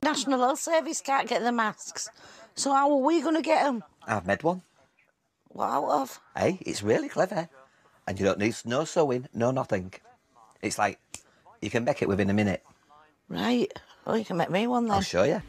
englishAccent.mp3